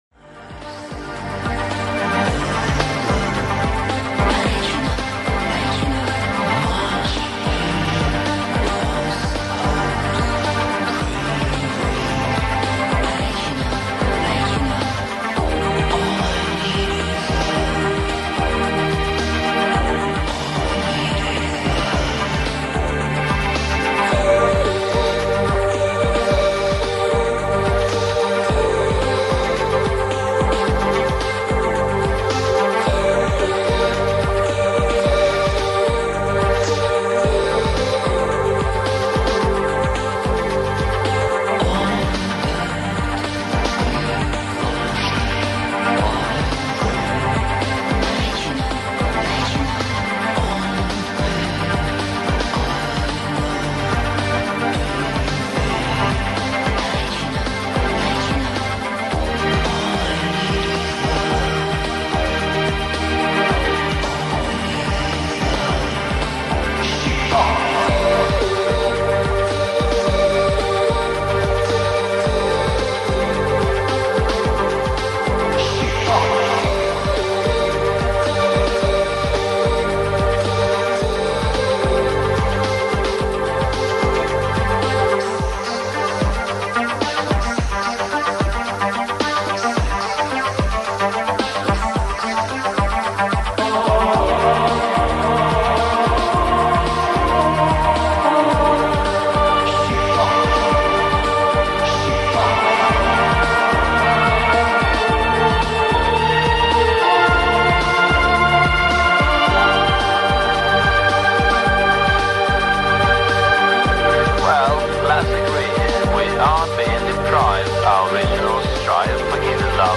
06 - LOUNGE